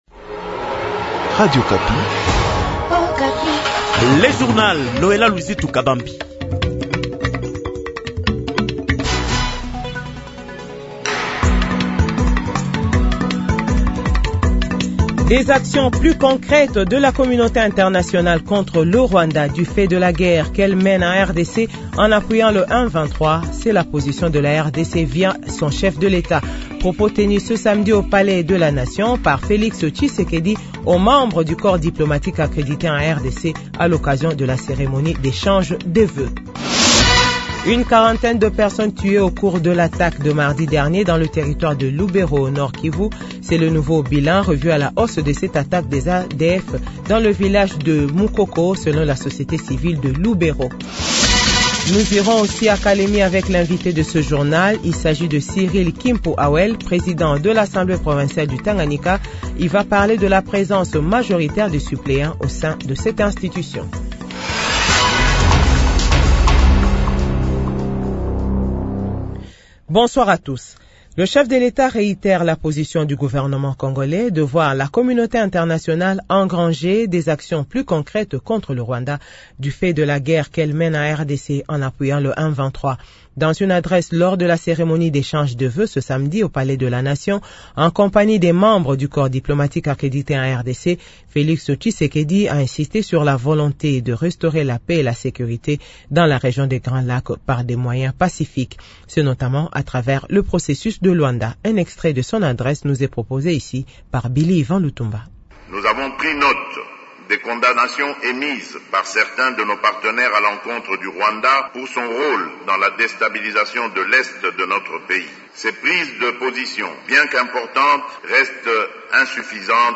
1/ Kinshasa : Extrait adresse Felix Tshisekedi devant les diplomates, a l occasion de la ceremonie d'echage des voeux